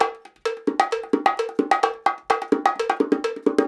130BONGO 01.wav